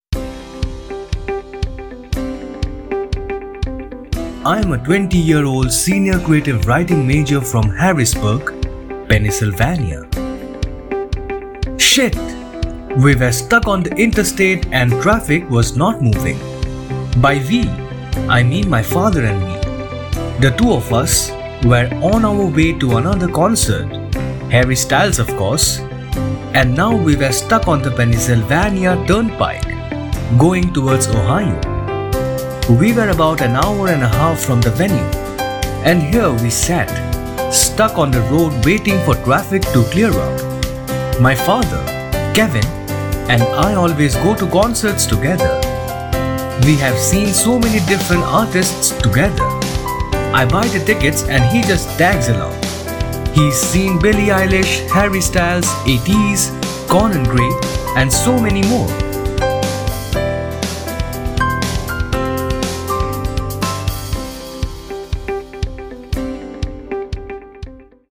Anything and Everything (Audio book)
Trailer